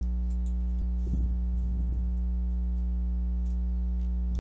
If that background noise sounds like a constant buzzing or humming, it’s a ground loop (Wikipedia: Ground Loop).
Microphone recording with ground loop noise (use headphones to listen):
recording-with-ground-loop-noise.m4a